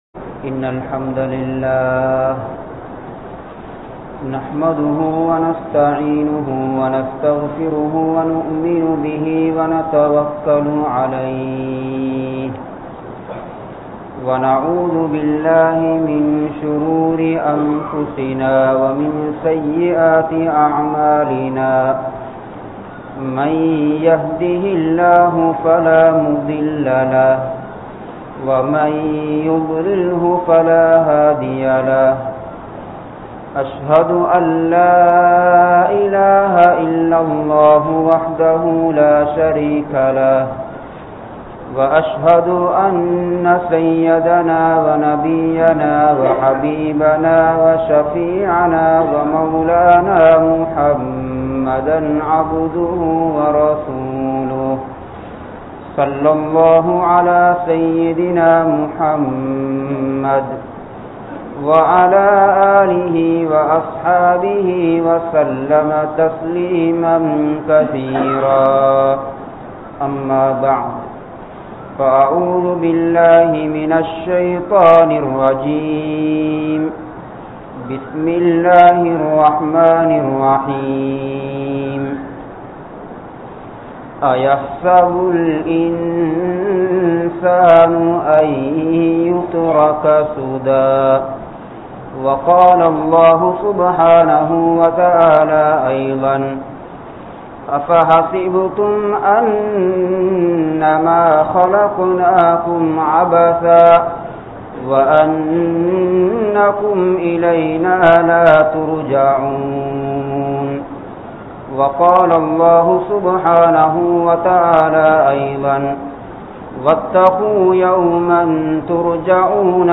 Vaalkaien Noakkam (வாழ்க்கையின் நோக்கம்) | Audio Bayans | All Ceylon Muslim Youth Community | Addalaichenai
Kattankudy, Jamiul Qarni Jumua Masjidh